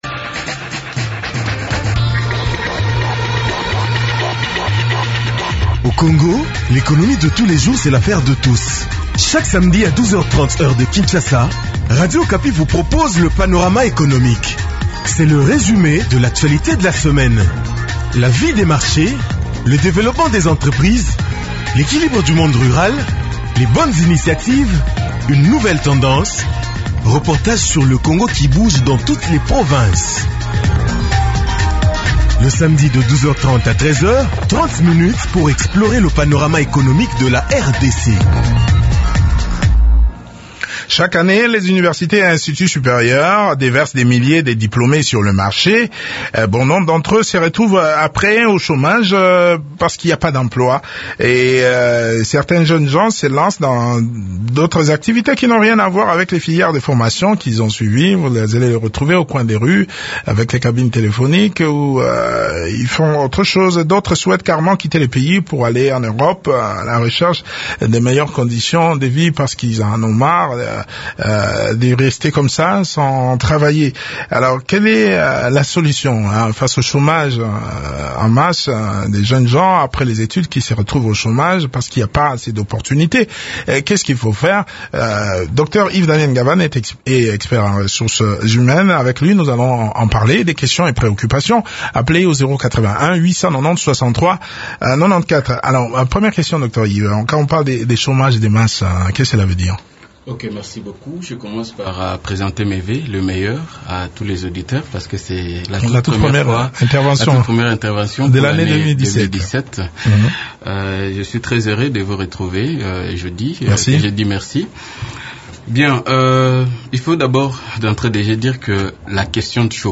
expert en ressources humaines.